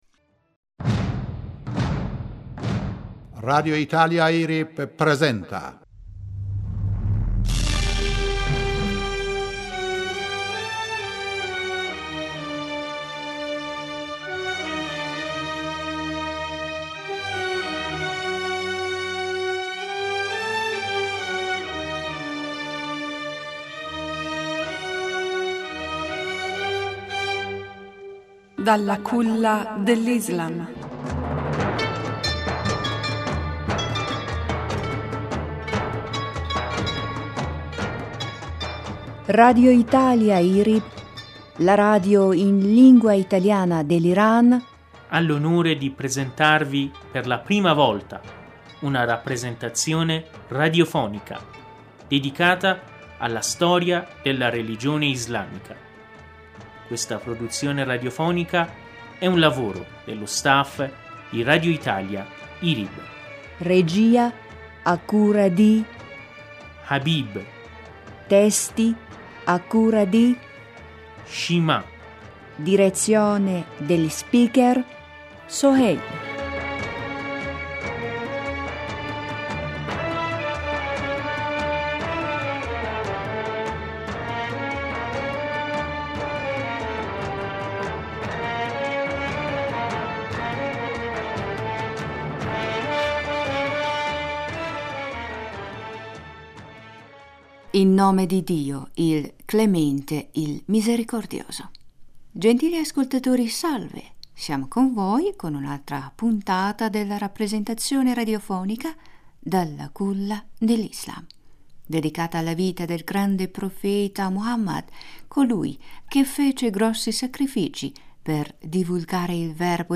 Siamo con con voi con un’altra puntata della rappresentazione radiofonica dalla Culla dell’islam, dedicata alla vita del grande profeta dell’islam Muhammad(as), colui che fece grossi sacrifici per divulgare il verbo divino. Amici nelle puntate precedenti vi abbiamo narrato che la Mecca fu conquistata dal Profeta Muhammad senza spargimento di alcun sangue.